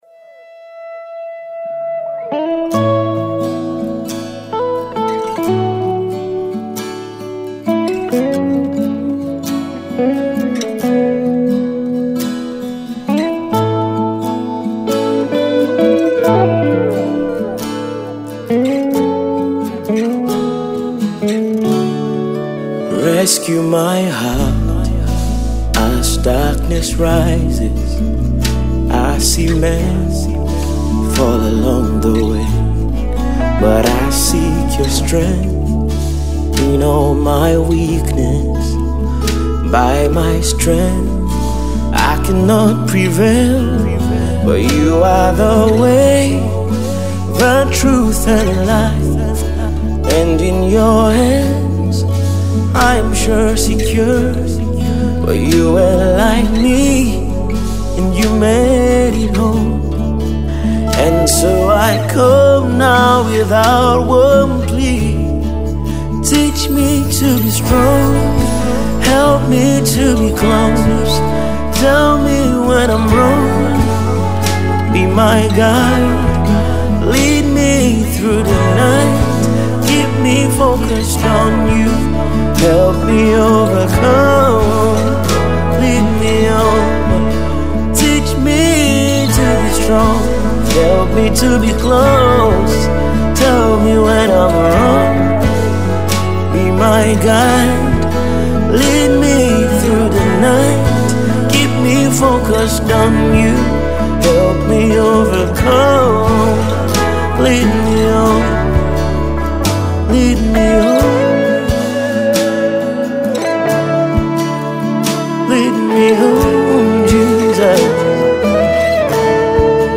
soul inspiring tune